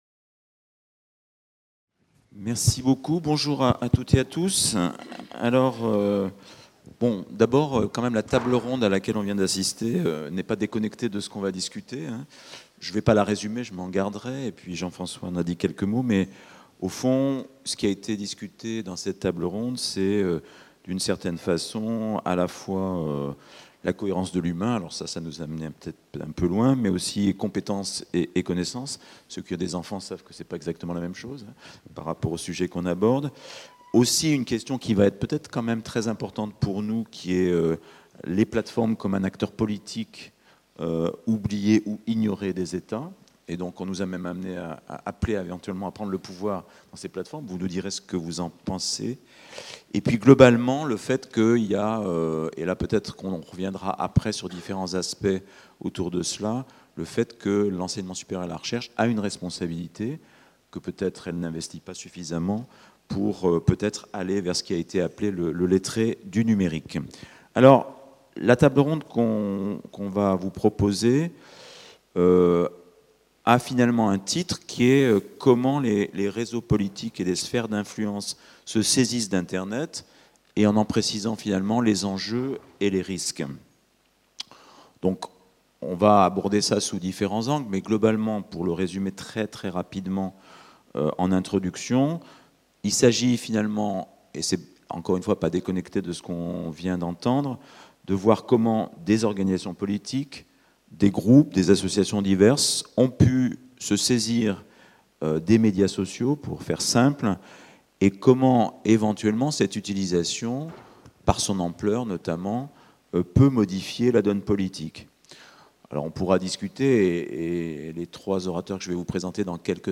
Colloque de La Conférence des présidents d’université (CPU) Université de Caen Normandie 30 novembre 2016 Devenir citoyen à l’ère du numérique : enjeux scientifiques et éducatifs